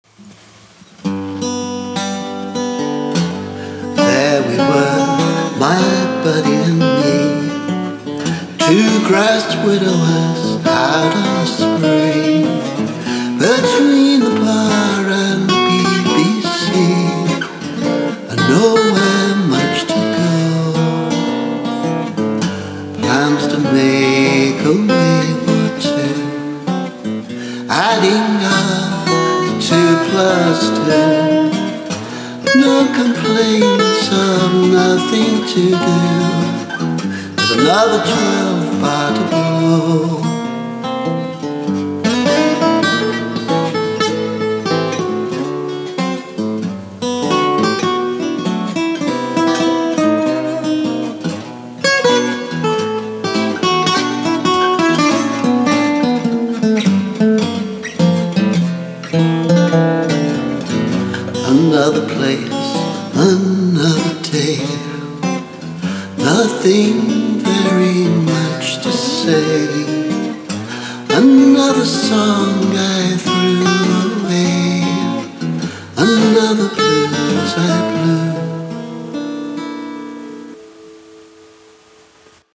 Actually a very rough demo, but there you go.
Remixed/remastered: